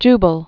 (jbəl)